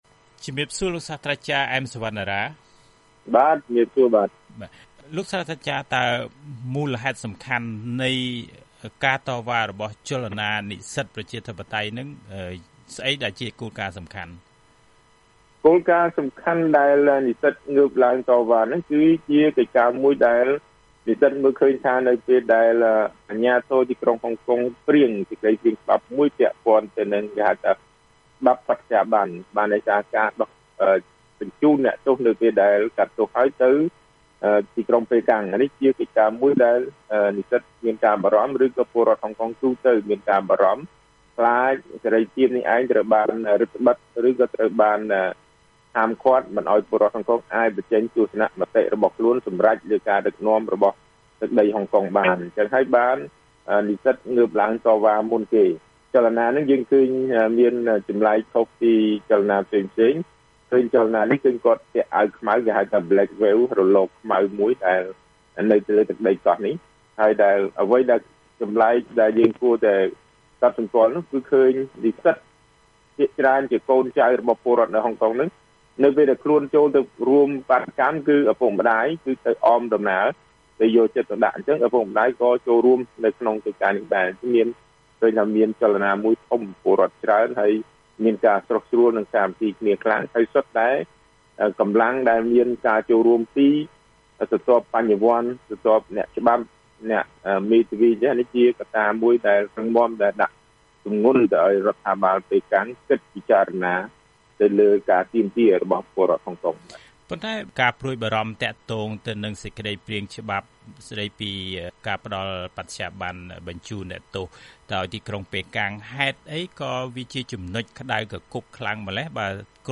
បទសម្ភាសន៍ VOA៖ ជោគជ័យរបស់ក្រុមបាតុករប្រជាធិបតេយ្យអាចជាមេរៀនសម្រាប់កម្ពុជា